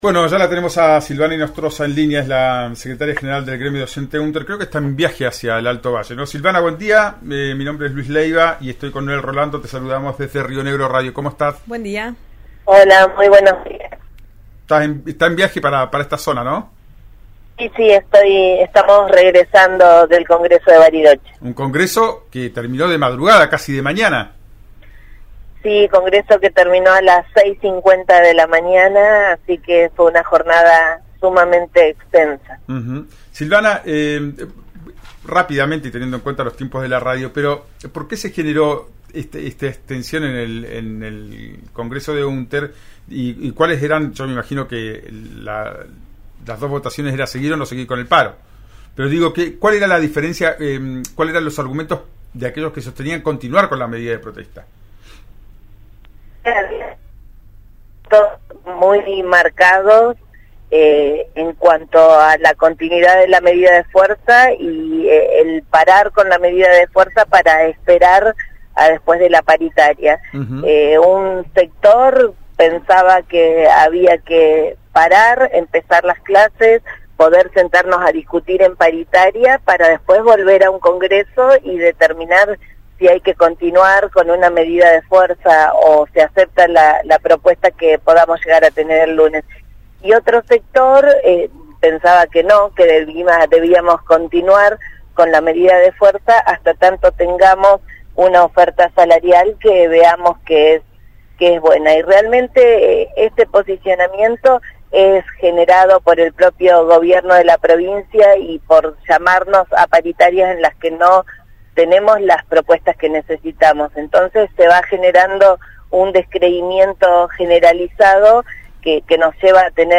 en «Ya es tiempo» por RÍO NEGRO RADIO